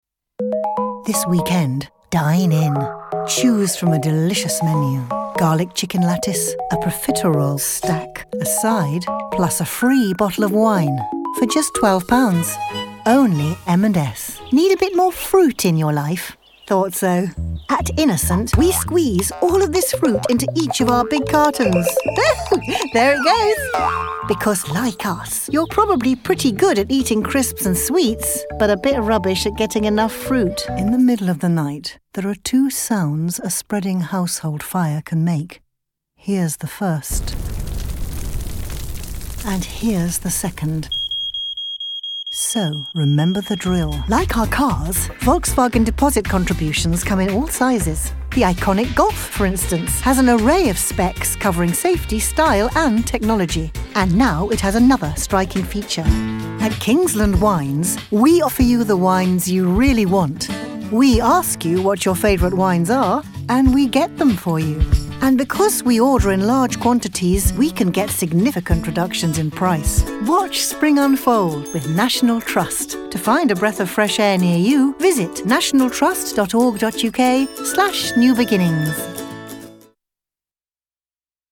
• Main Voice Reel